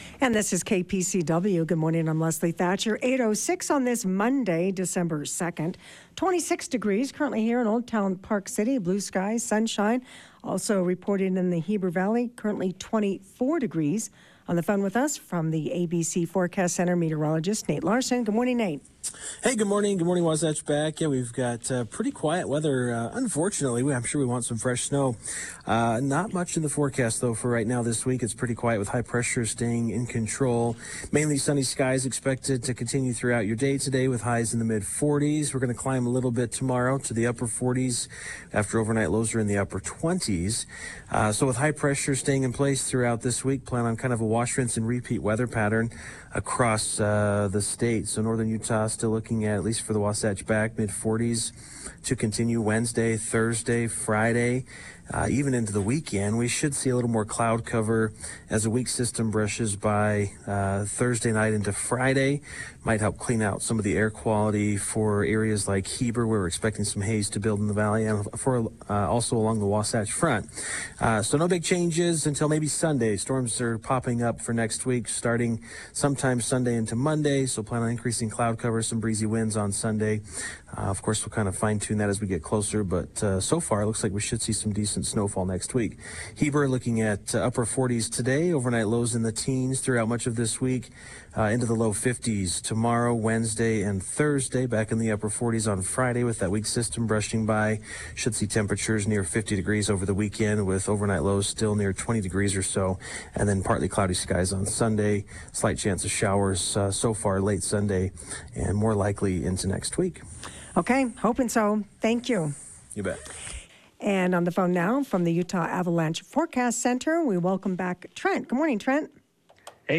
Get the information directly from the people making news in the Wasatch Back with live interviews every weekday.